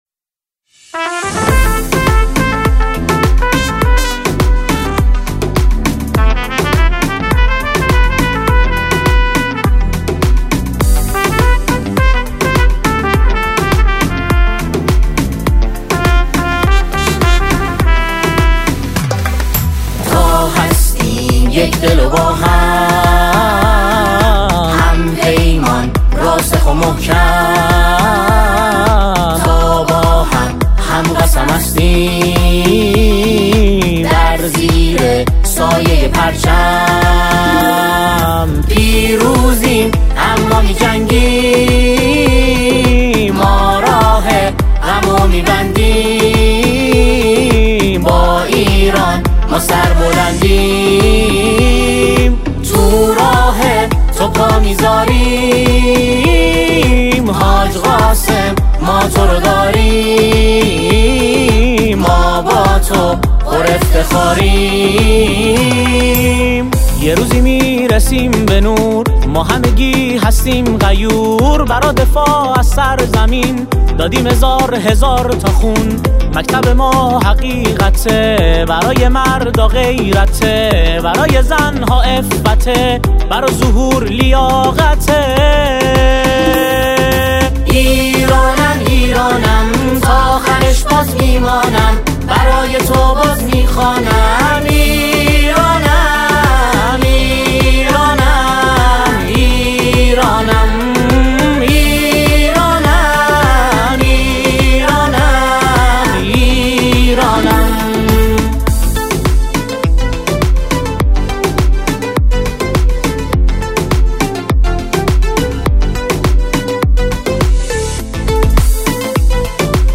نماهنگ دهه فجر | «جانم ایران» - گروه سرود به رنگ آسمان آستانه اشرفیه (کلیپ، صوت، متن)